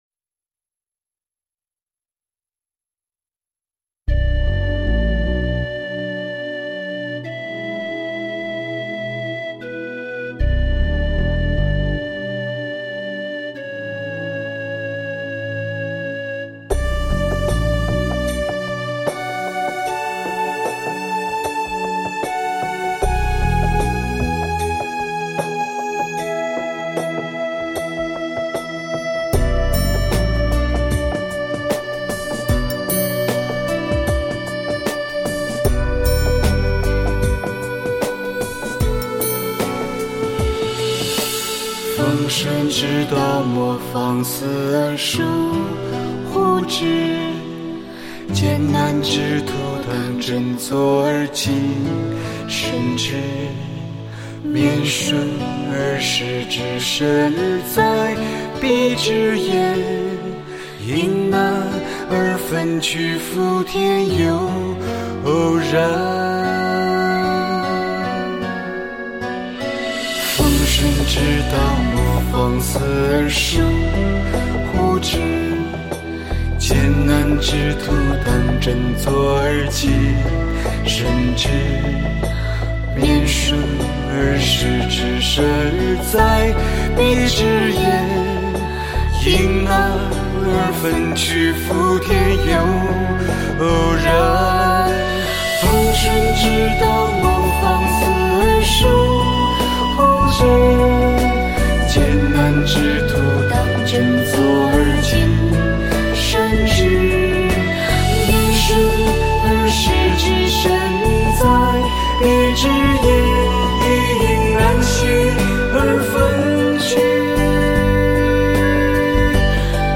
【平缓的节拍、方正的乐句、安然叙述，教化心灵】